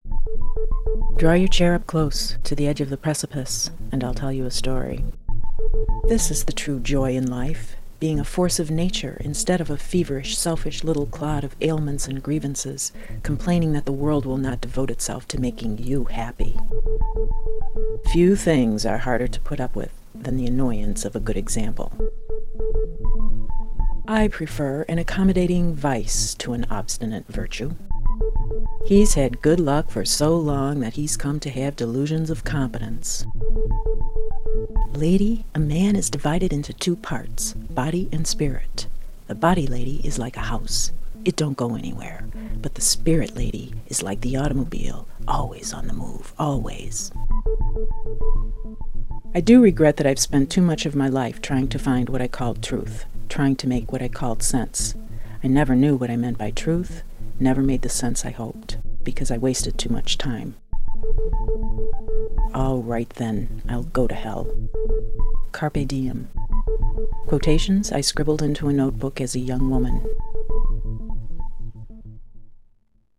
This radio piece was developed for submission to the "Short List" collaboration between Transom and NPR's Day to Day.
Listen in mono